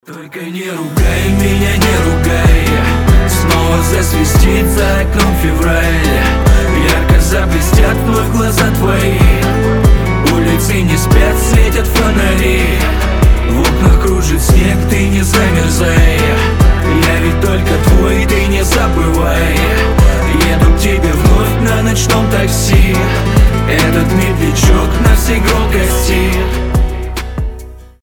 • Качество: 320, Stereo
лирика
дуэт
медленные